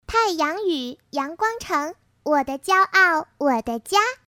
当前位置：首页 > 配音题材 > 童声配音
童声配音即儿童音或者少年音，也叫小孩音。